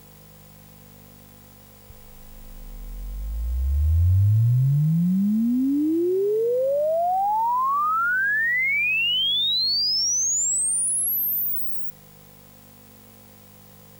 sid-measurements - SID chip measurements